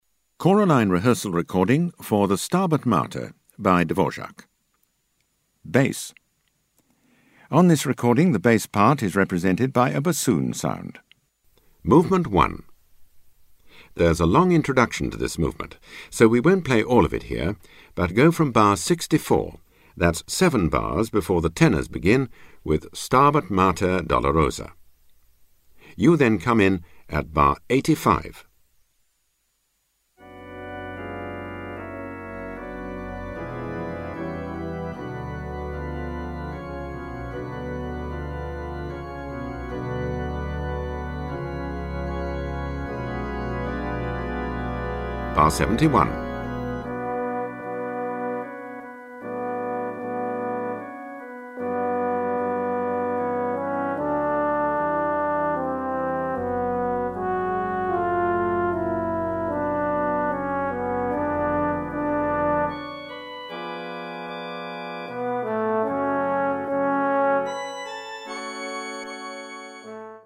Bass
High Quality made by BBC Sound Engineer
Easy To Use narrator calls out when to sing
Don't Get Lost narrator calls out bar numbers
Be Pitch Perfect hear the notes for your part
Vocal Entry pitch cue for when you come in